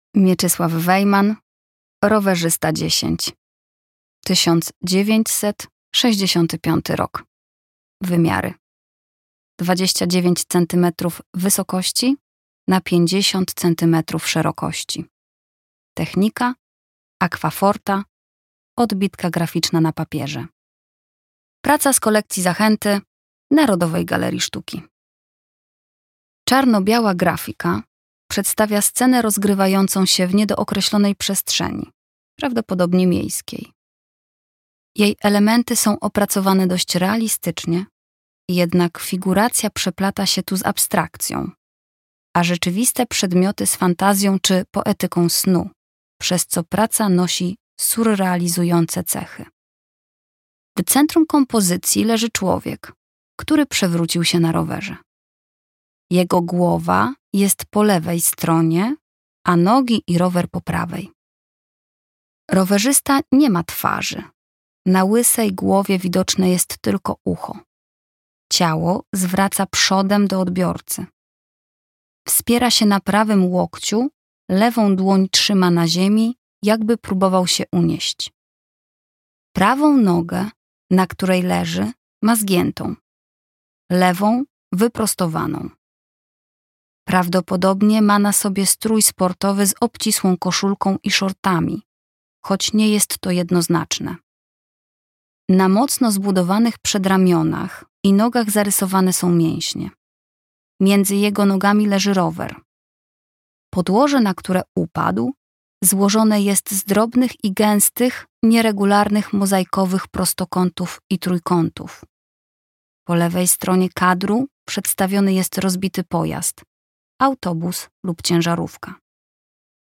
audiodeskrypcja